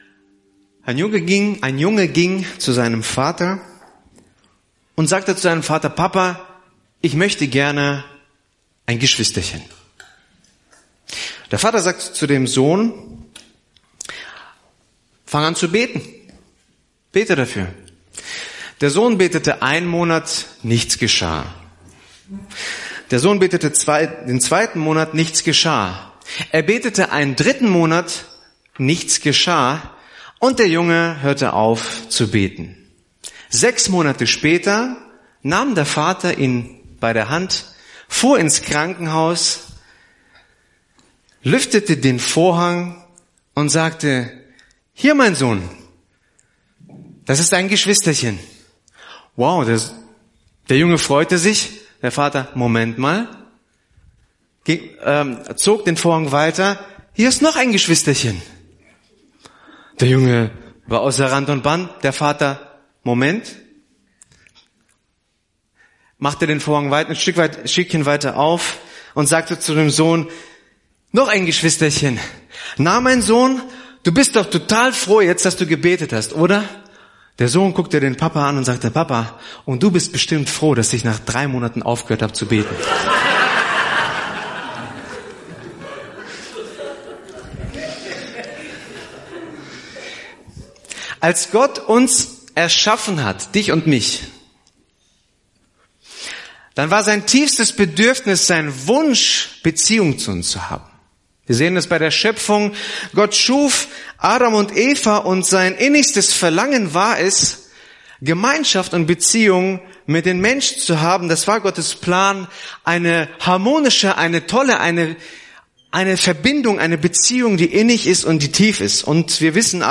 Begleitmaterial zur Predigt: